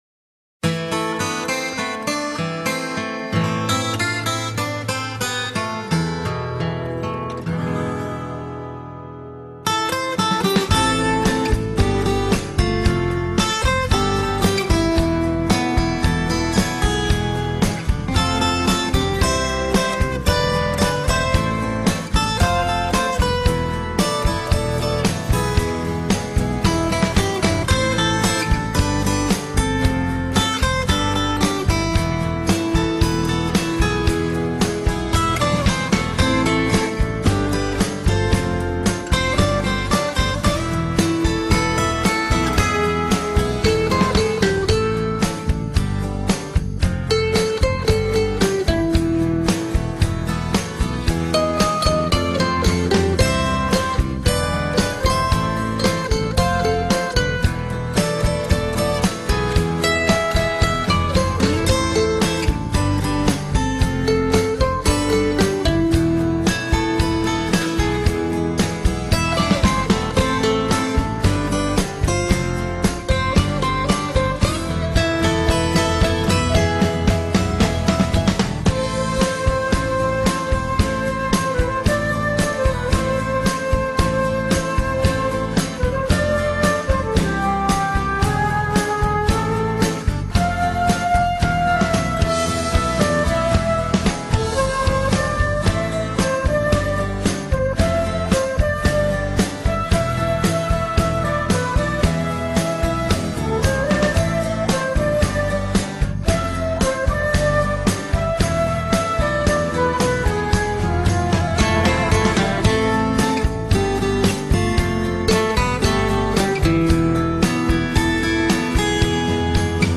instrumental-planetas.mp3